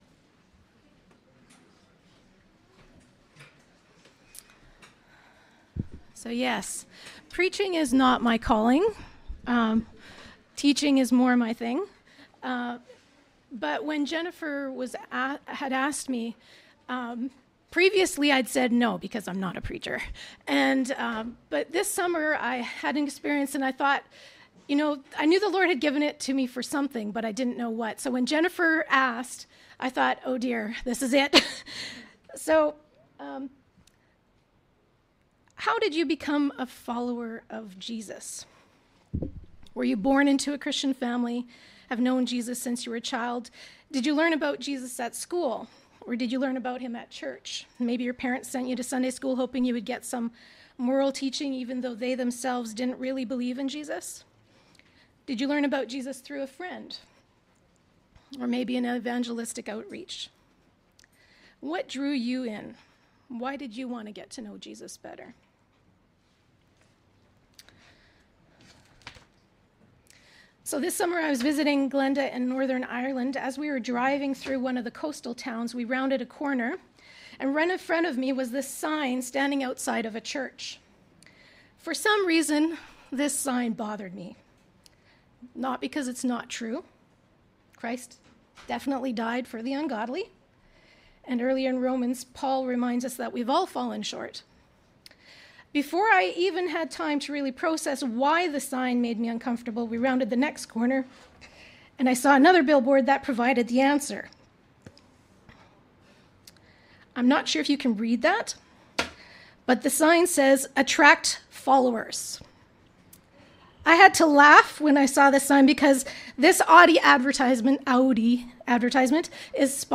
Sep 03, 2023 The Call To Follow MP3 SUBSCRIBE on iTunes(Podcast) Notes Discussion The call to follow and The call to fellowship. Sermon By